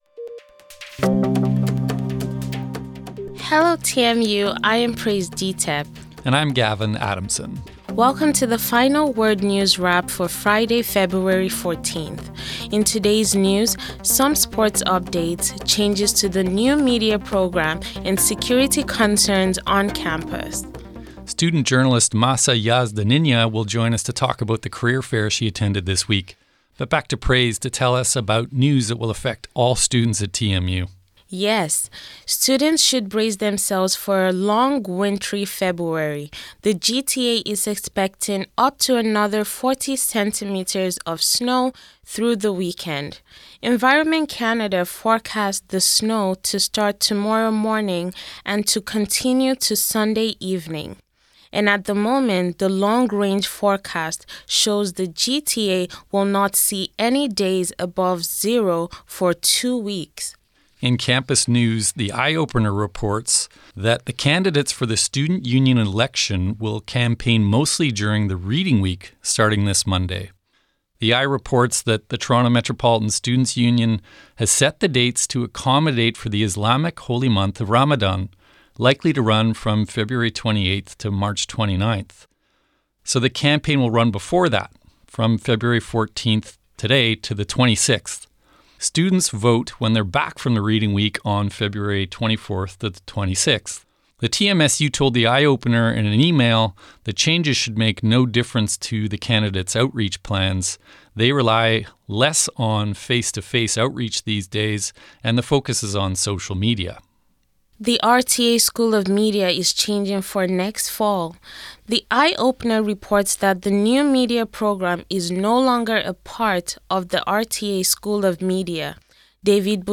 This is the Met Radio Newswrap produced for The Final Word on 1280 AM in Toronto,  Fridays at 5:30 pm, during the winter term 2025 by first-year graduate students in journalism at TMU.